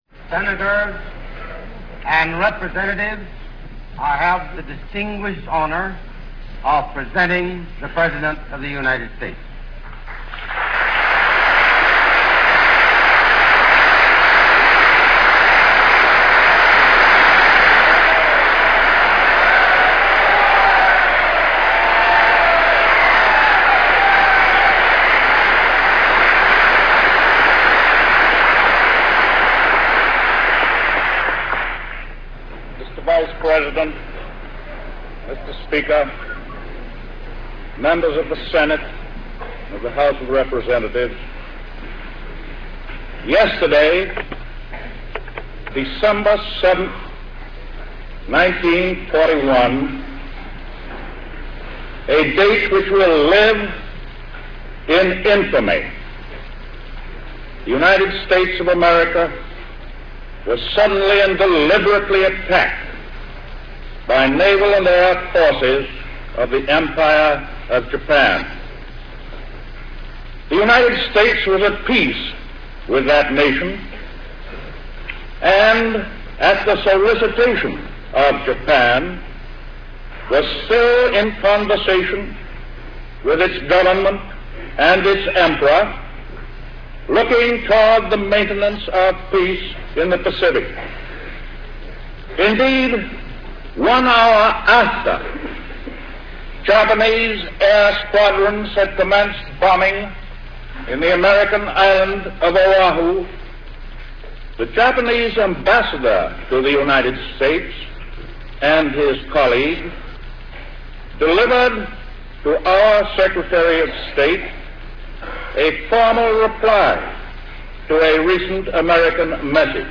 Listen to Franklin Delano Roosevelt's "Day of Infamy" speech